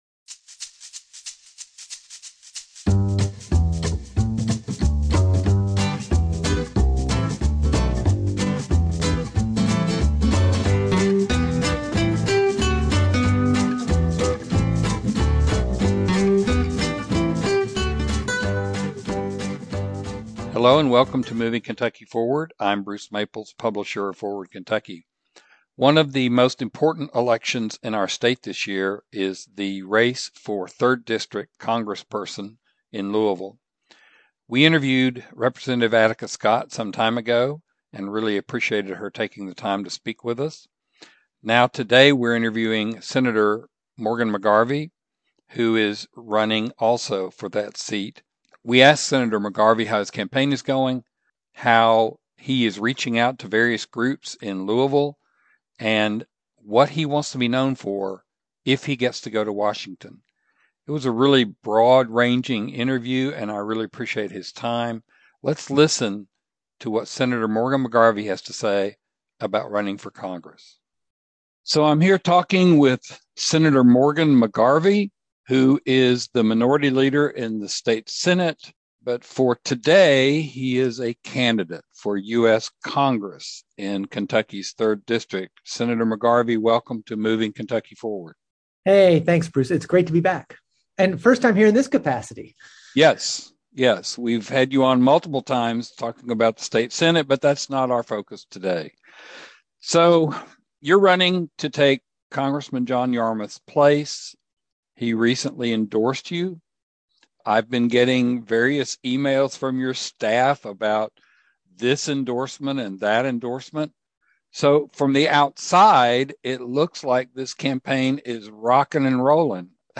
Moving Kentucky Forward An interview with Morgan McGarvey